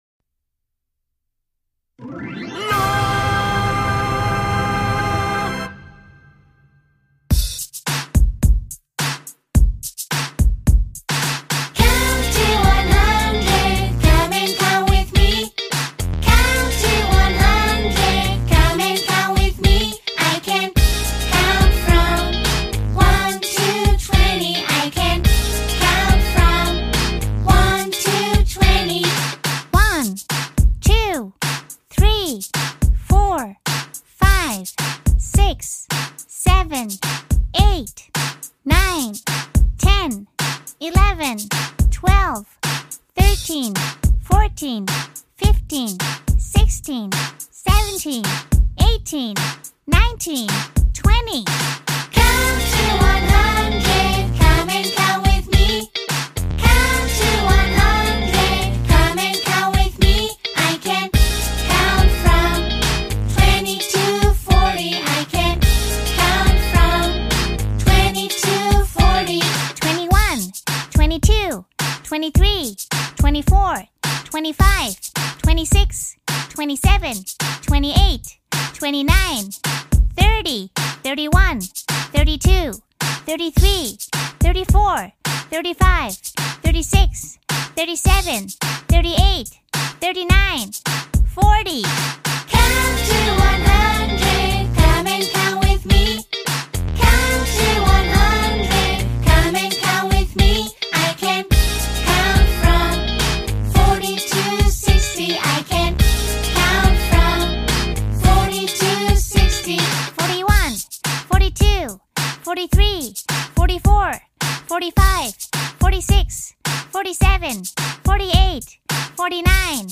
Join us in this fun and interactive numbers song, where kids can learn to count from 1 to 100 through engaging animations and catchy music! Perfect for toddlers, preschoolers, and young learners, this song makes counting easy and enjoyable.